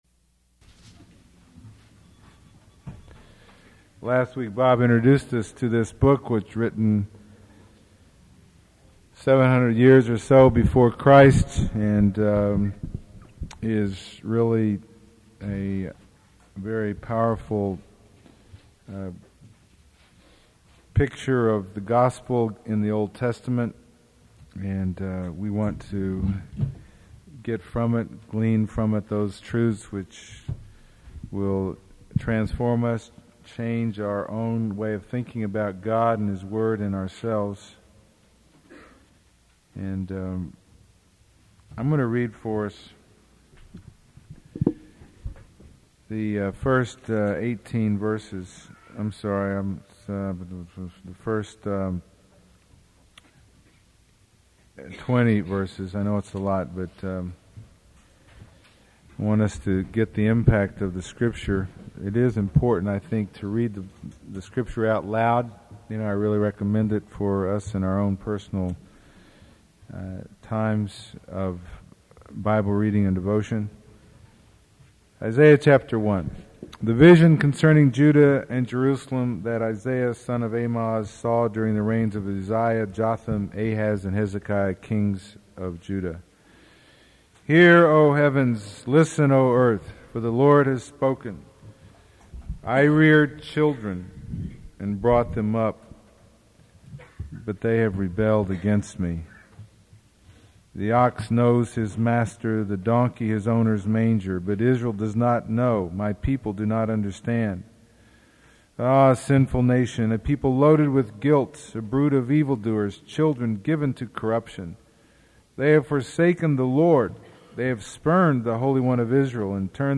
Sermon 26 | The Bronx Household of Faith